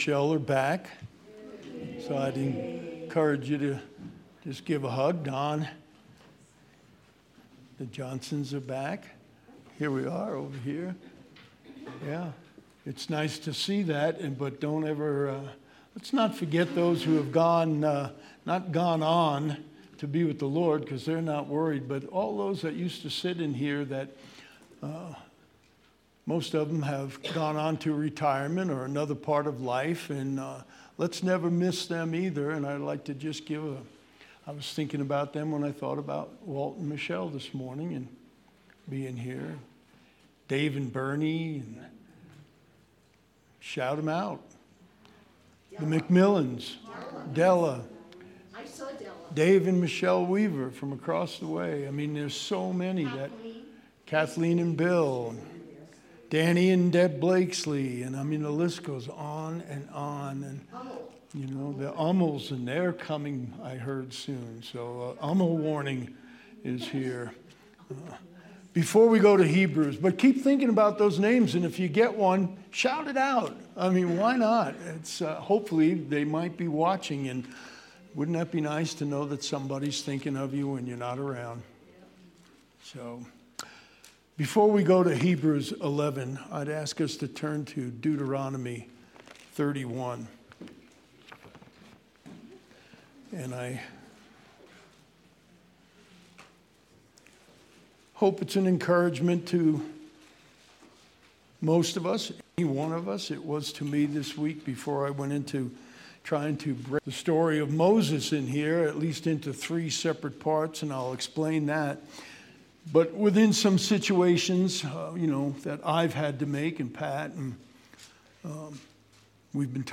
June 11th, 2023 Sermon